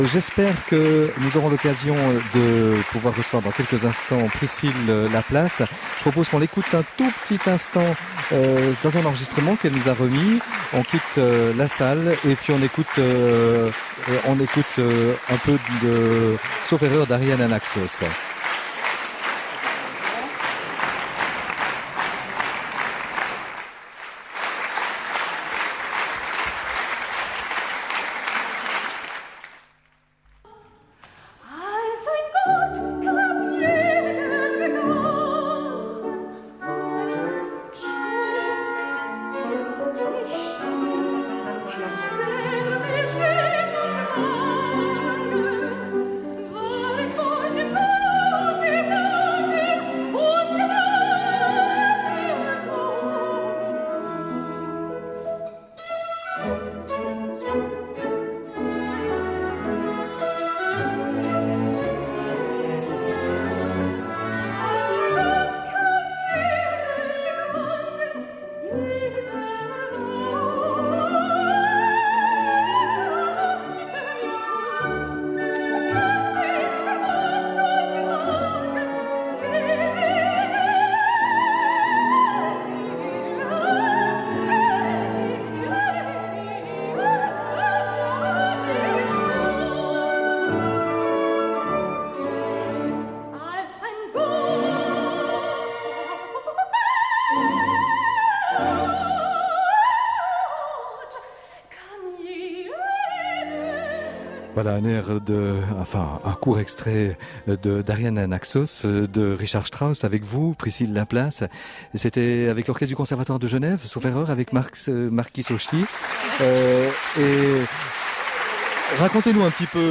icone_pdf Interview du concert du 10 juin 2007 en direct de l'Aula des Cèdres à Lausanne
interview-cedres.mp3